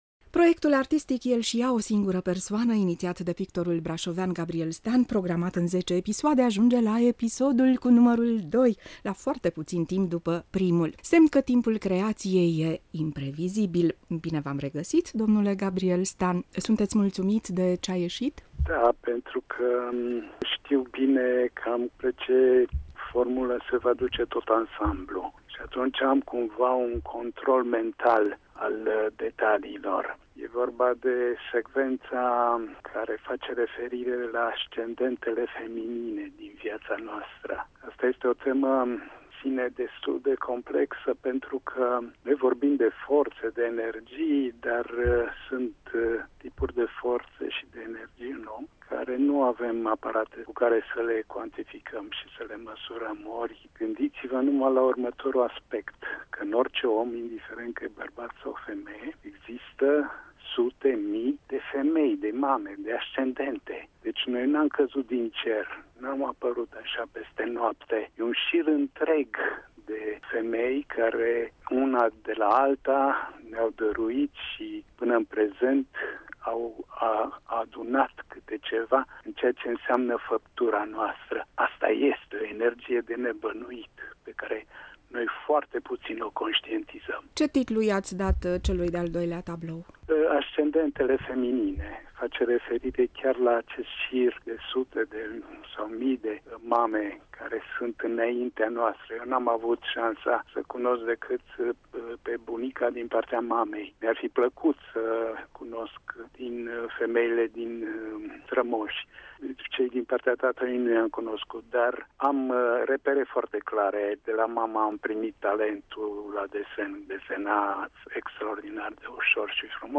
Ascultați aici interviul realizat în exclusivitate sau în emisiunea Pasaj urban -proiecte online, de sâmbãtã, 9 mai, ora 20:00.